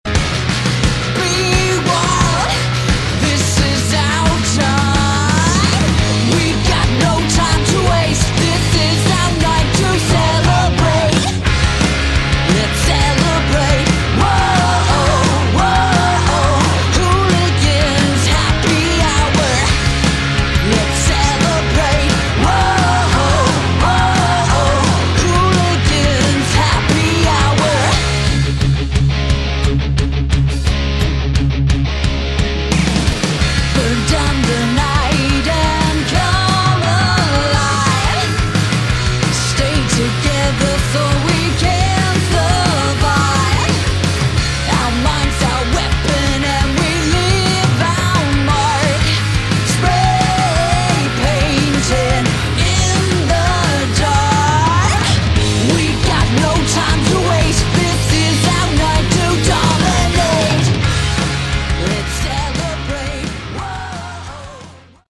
Category: Hard Rock/Punk Rock
vocals
drums
bass
guitar